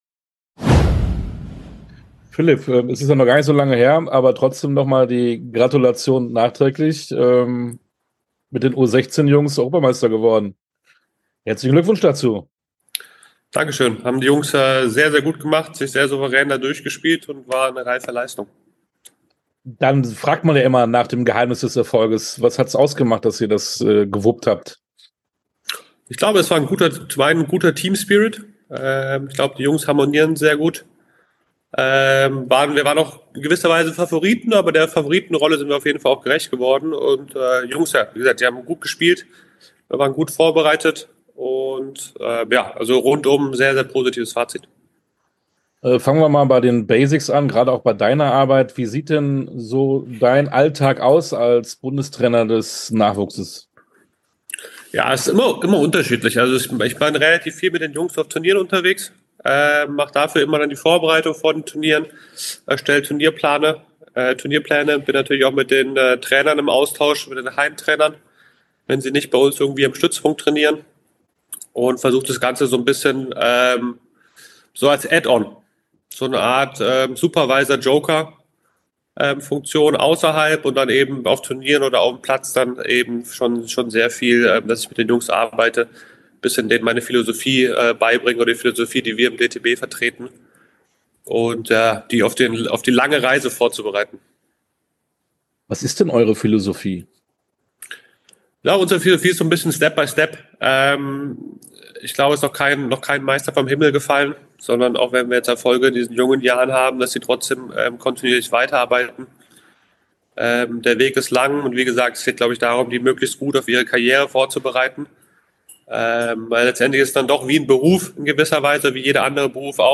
Sportstunde - Interview komplett Philipp Petzschner, Tennis Bundestrainer Nachwuchs ~ Sportstunde - Interviews in voller Länge Podcast
Interview_komplett_Philipp_Petzschner-_Tennis_-_Bundestrainer_Nachwuchs.mp3